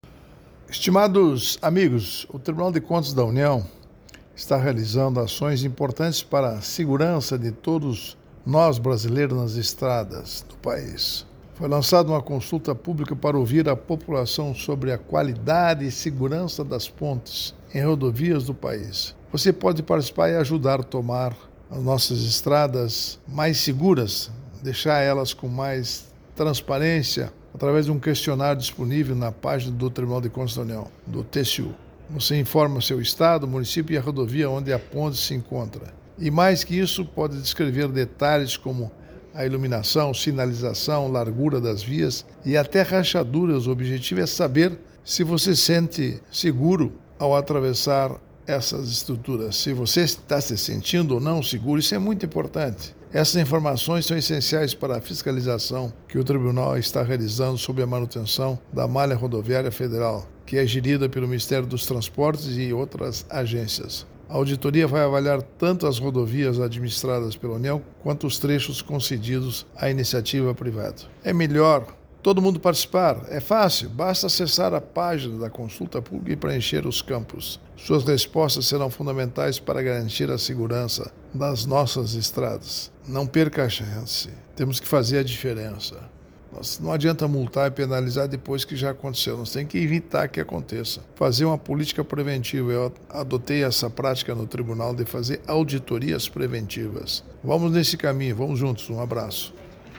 Comentário de Augusto Nardes, ministro TCU.